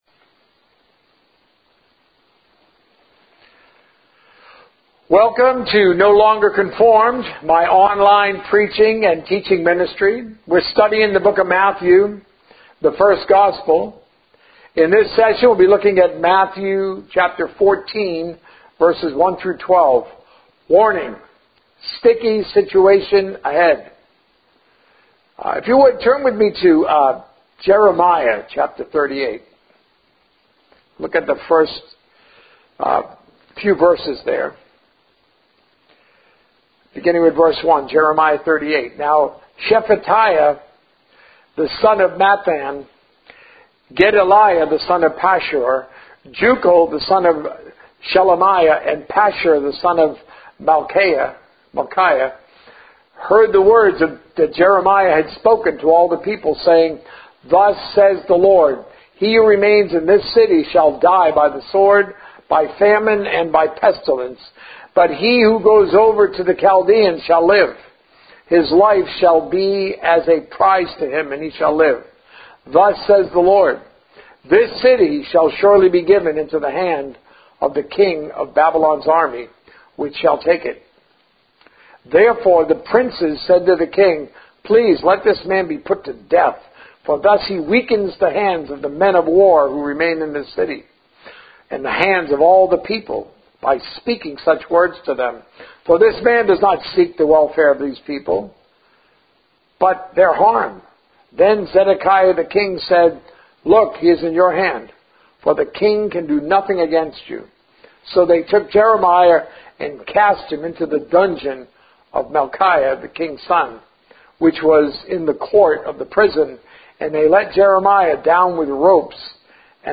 A message from the series "The Source of Success."